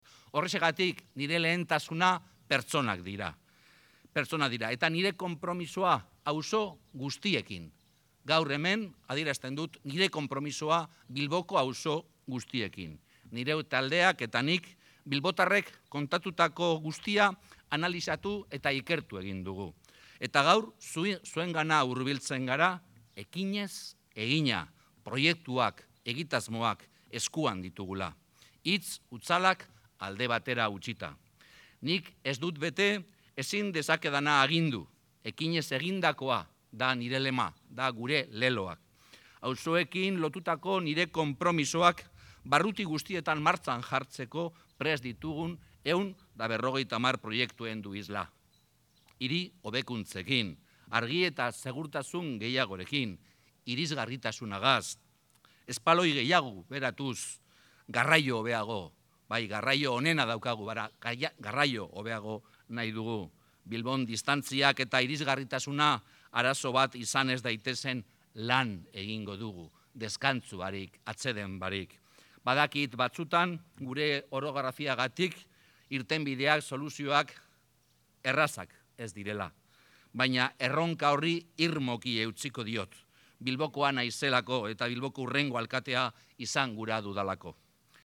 Juan Mari Aburtoren hitzaldia Arabellan